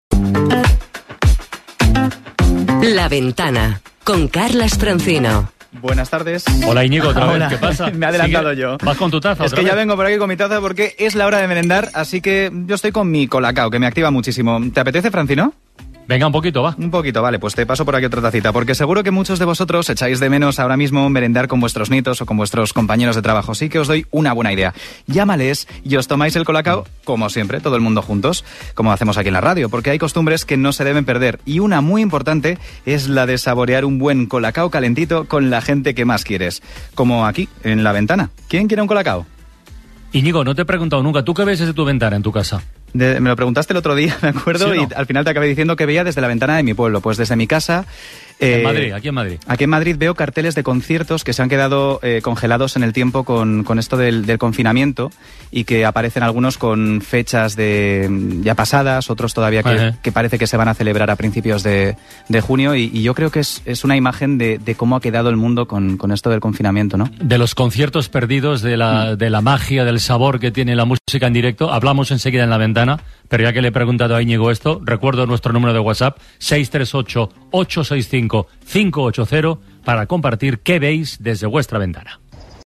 Aprovechando la intervención, y muestra de cómo la publicidad queda mucho más integrada cuando se realiza en directo, Francino me preguntó qué veo por mi ventana.